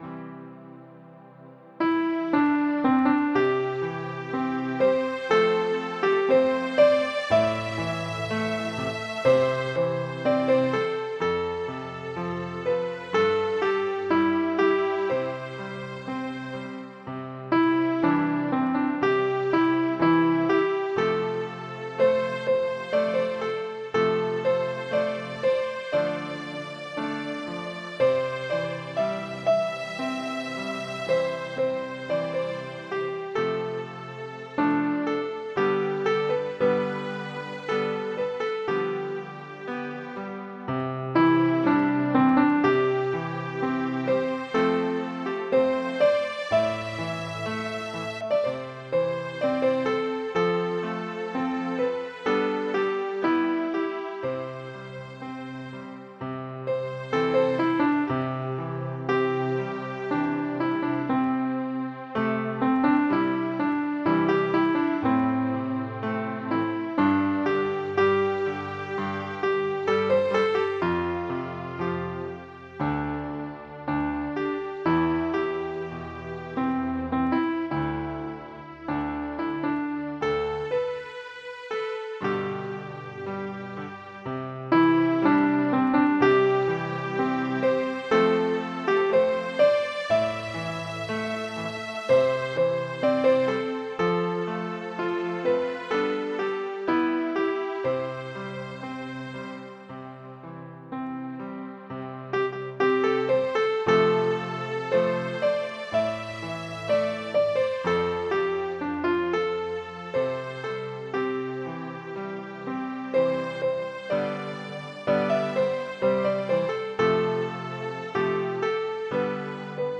Audio Midi Bè 1: download